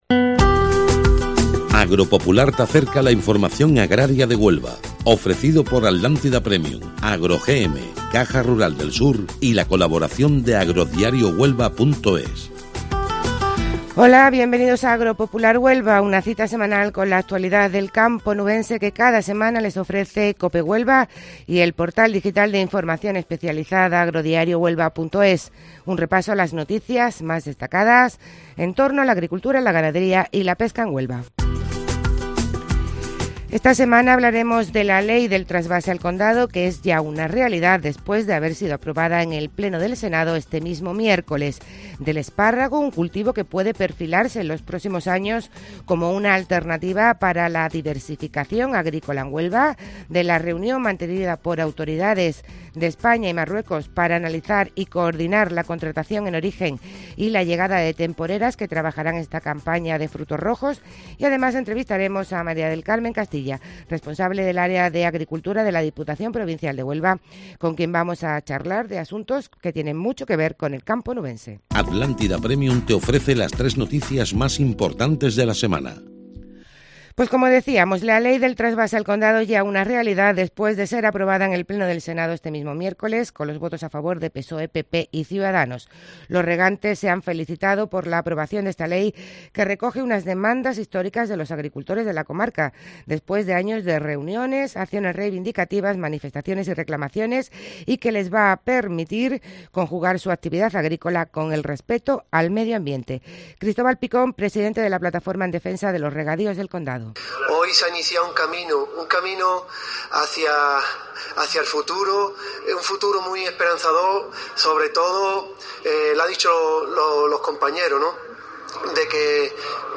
Y además entrevistaremos a María del Carmen Castilla, responsable del área de Agricultura de la Diputación Provincial de Huelva